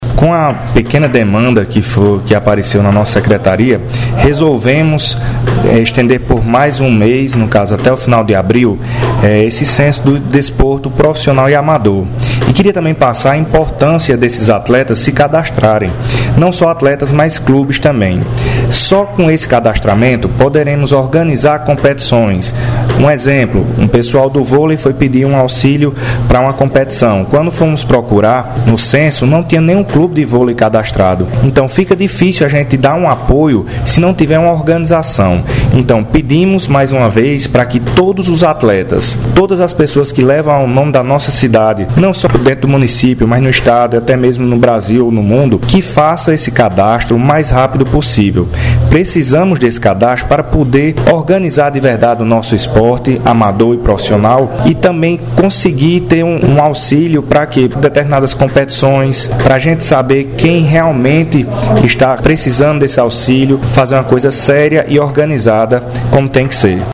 Fala do secretário de Esporte e Turismo, Nalfrânio Sátiro –